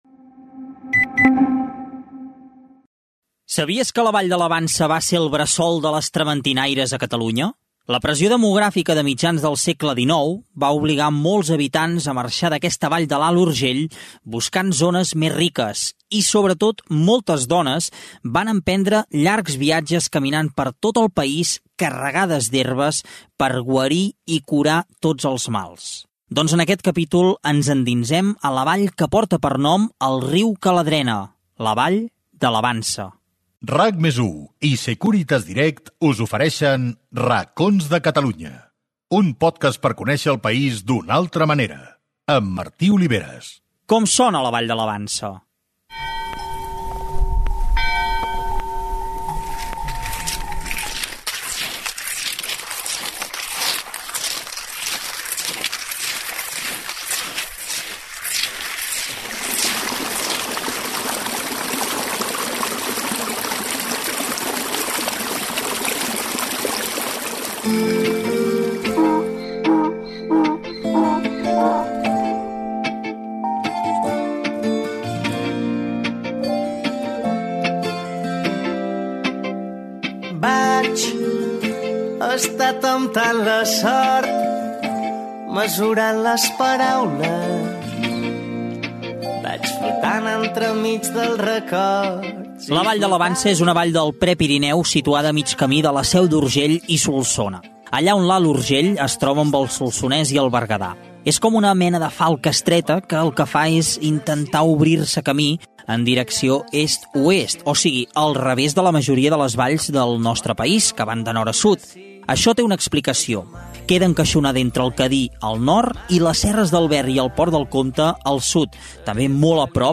Espai dedicat a La Vall de la Vansa de l'Alt Urgell Gènere radiofònic Entreteniment